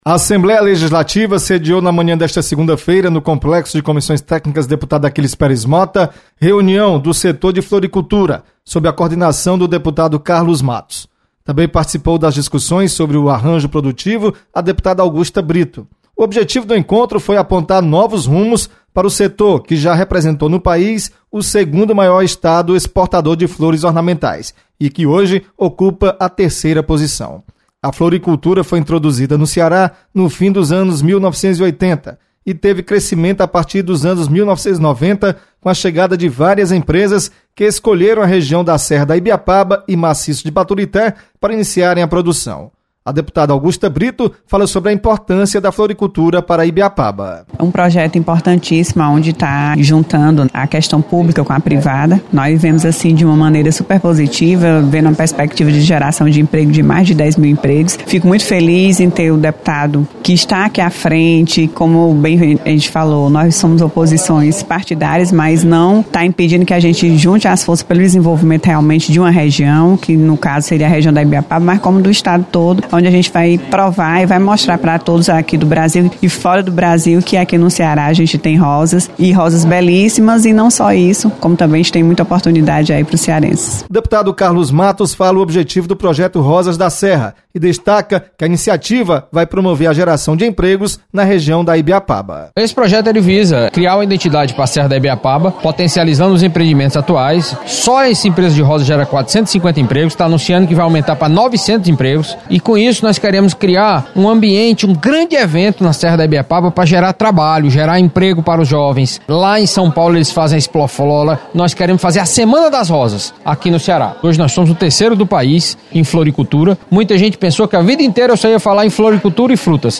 Deputada Augusta Brito e deputado Carlos Matos participam de reunião sobre arranjo produtivo da floricultura. Repórter